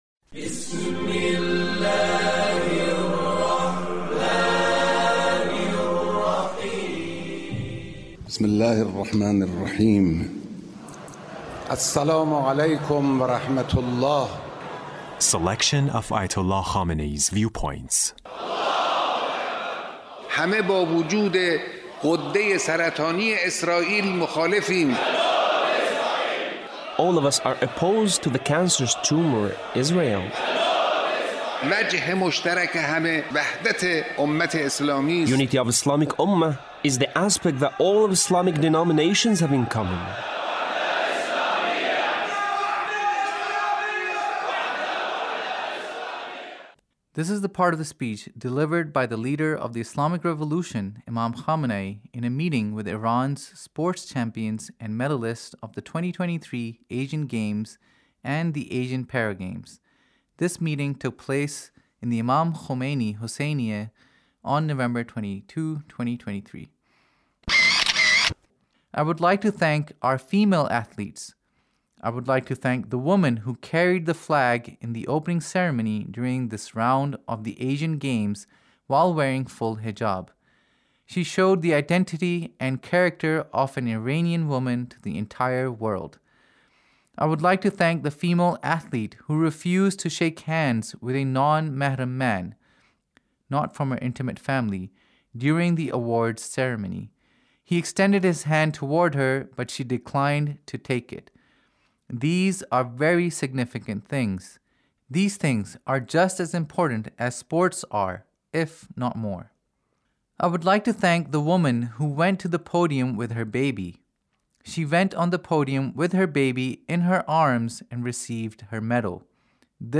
Leader's Speech in a meeting with Iran’s sports champions and medalists